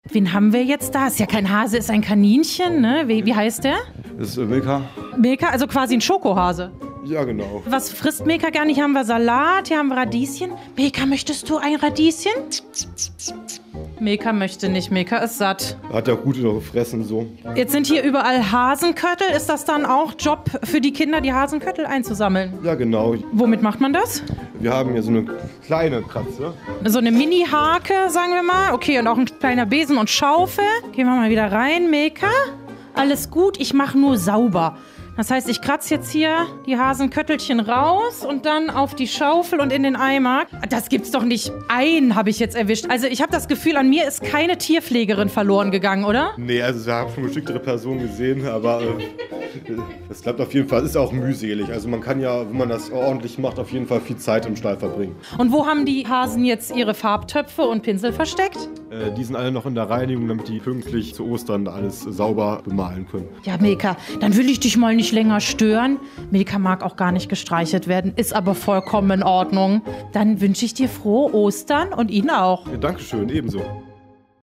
repo_fauna_3_hasenstall_ausmisten.mp3